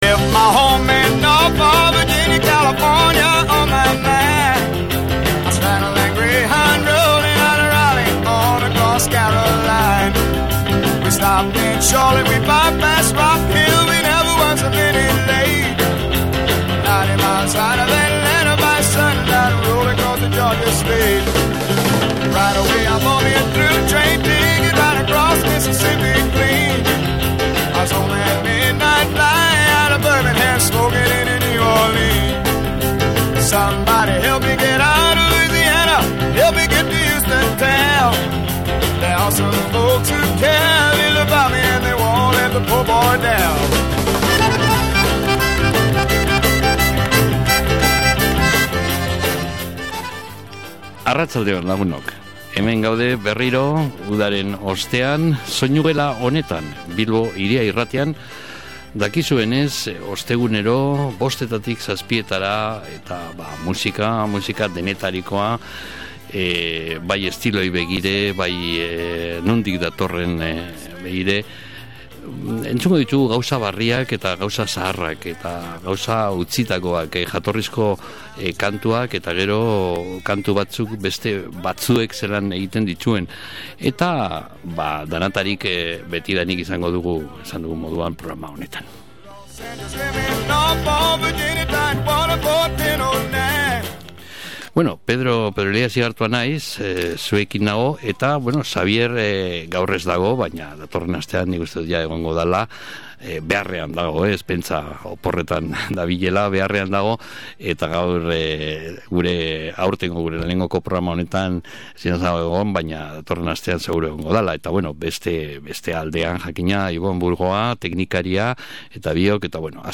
reggae klasikoa
jazz edota soul estiloetan moldatuta